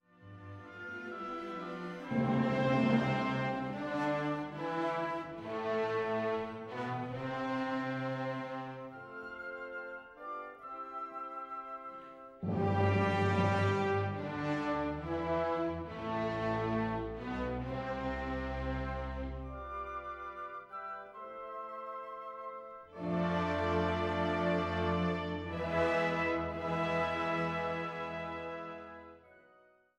Andante con moto